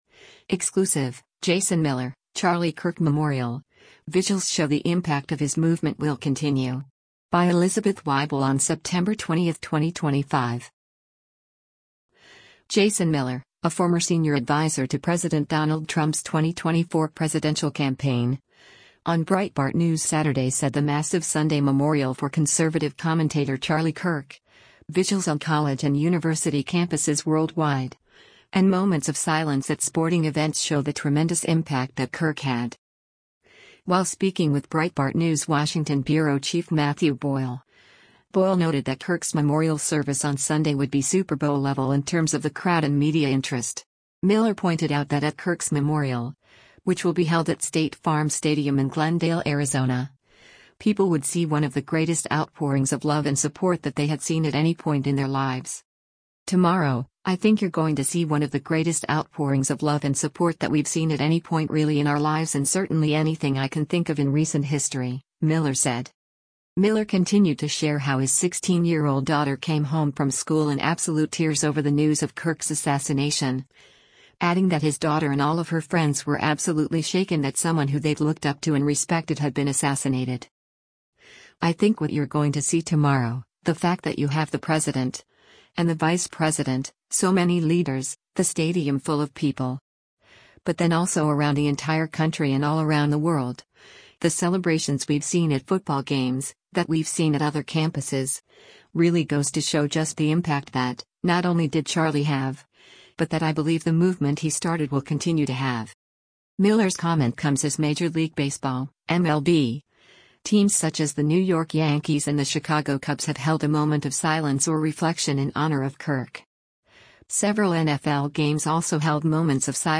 Jason Miller, a former senior adviser to President Donald Trump’s 2024 presidential campaign, on Breitbart News Saturday said the massive Sunday memorial for conservative commentator Charlie Kirk, vigils on college and university campuses worldwide, and moments of silence at sporting events show the tremendous impact that Kirk had.